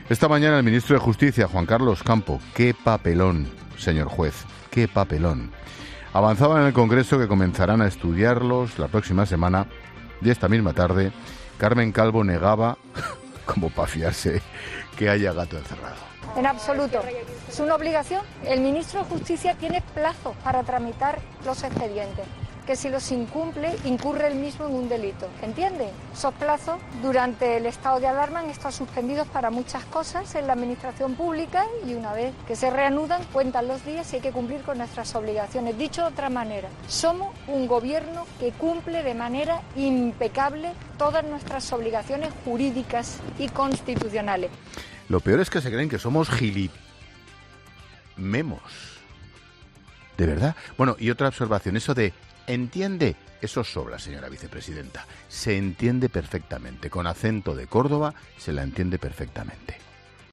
Ángel Expósito ha aprovechado el monólogo inicial de 'La Linterna' de este miércoles para cargar contra Carmen Calvo.
Para añadir después, entre risas: “Esta misma tarde, Carmen Calvo negaba (como para fiarse) que haya gato encerrado”.